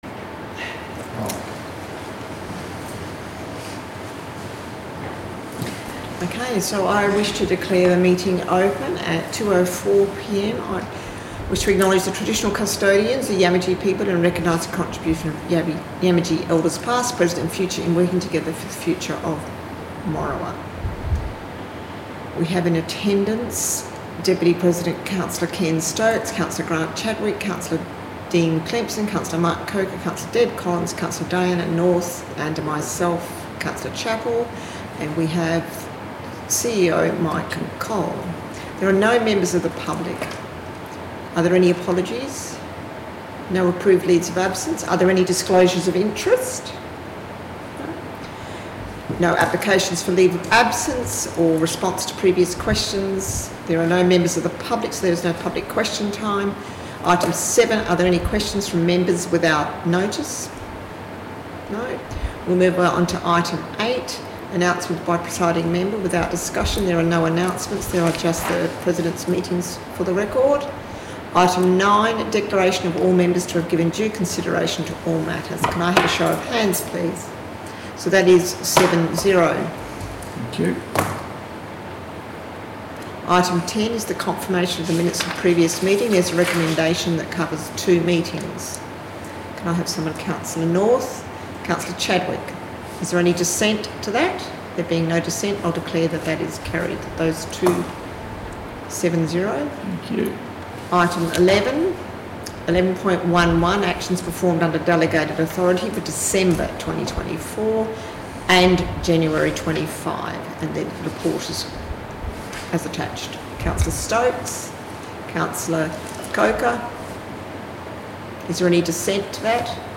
3 February 2025 Ordinary Council Meeting Minutes - Recording Part 1